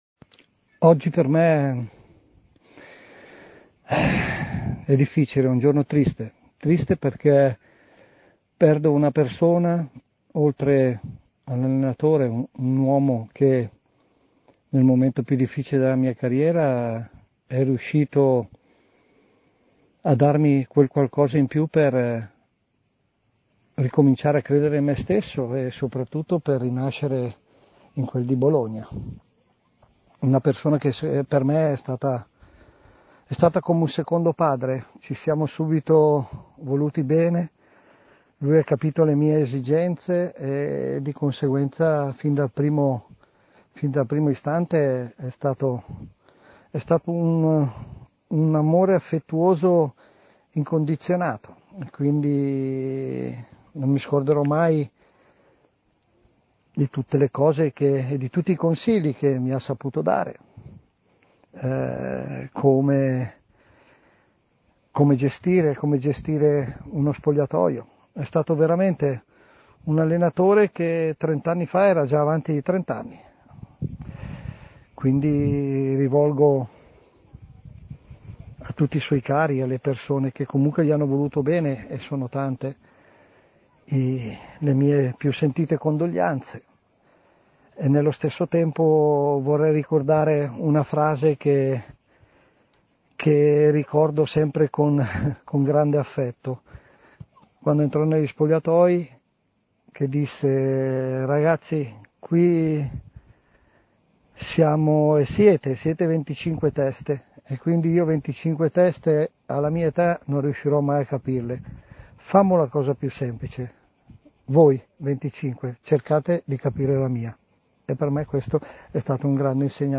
L’ex calciatore Giuseppe Signori è intervenuto ai microfoni di TMW Radio per ricordare così Carlo Mazzone: